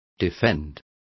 Complete with pronunciation of the translation of defend.